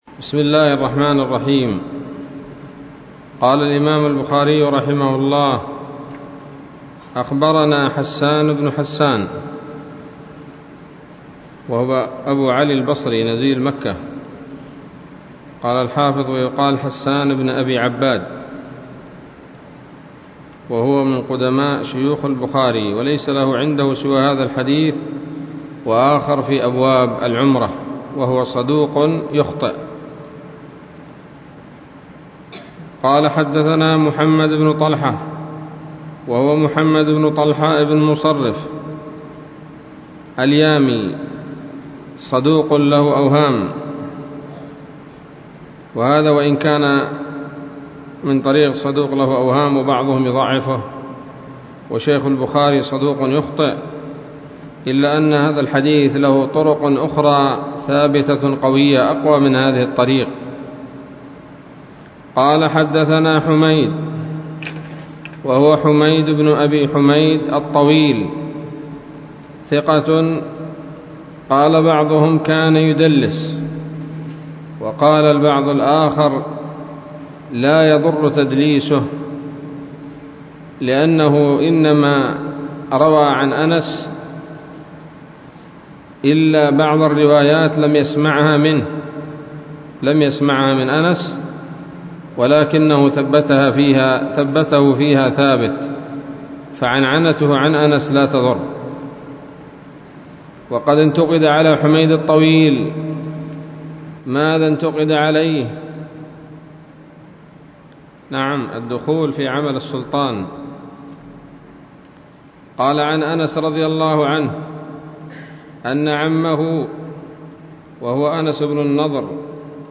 الدرس الثاني والثلاثون من كتاب المغازي من صحيح الإمام البخاري